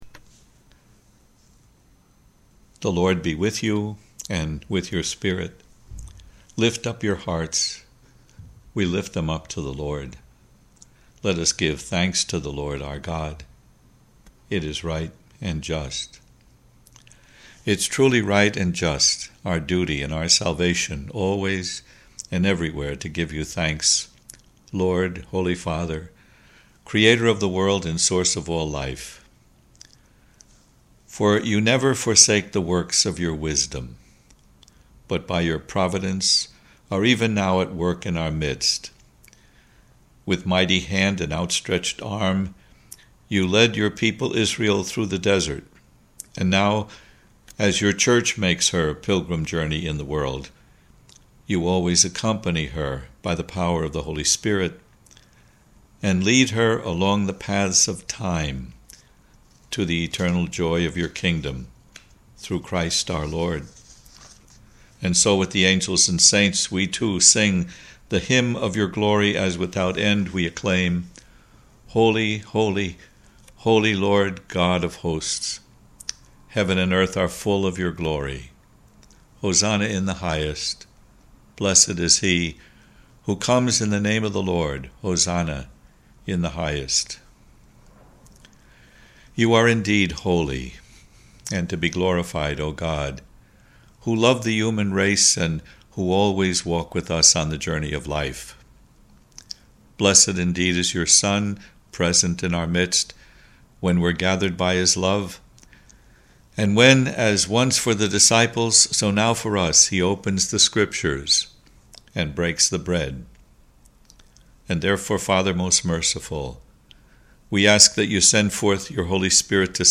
I recorded an audio file of the 2nd Eucharistic Prayer for Various Needs and you can listen to it at the end of this blog, if you wish.
Eucharistic Prayer
eucharistic-prayer.mp3